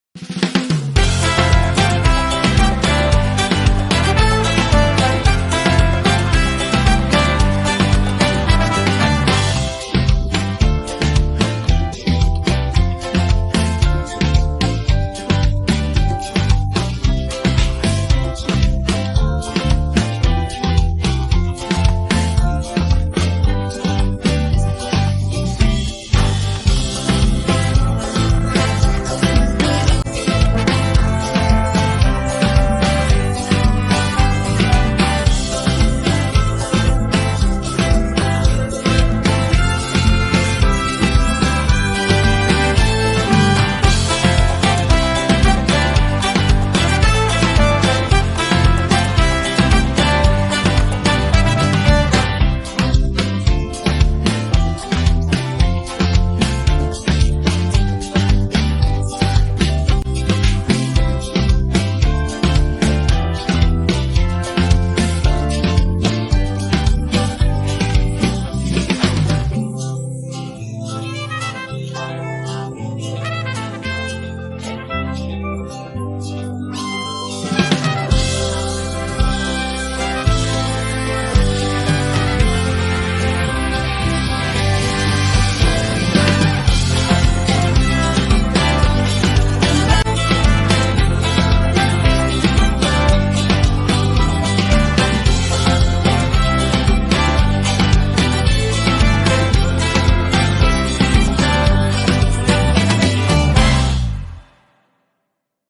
Cançó instrumental: